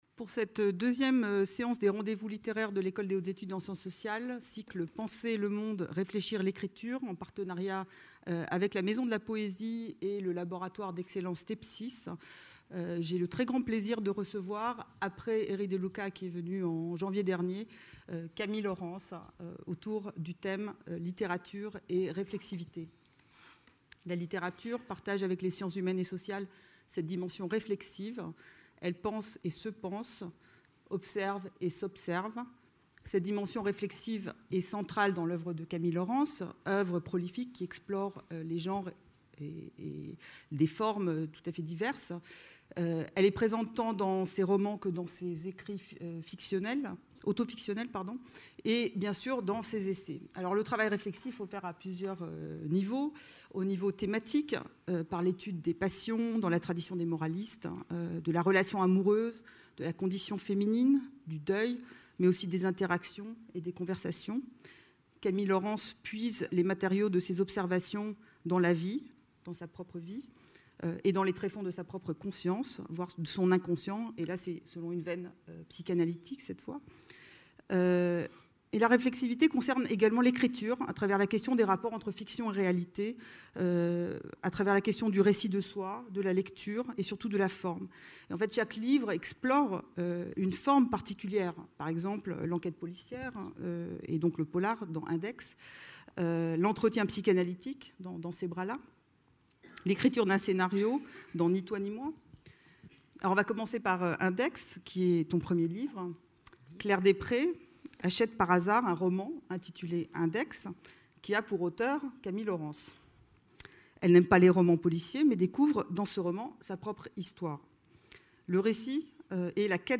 Littérature et réflexivité : Camille Laurens en dialogue avec Gisèle Sapiro | Canal U